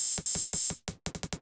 UHH_ElectroHatC_170-04.wav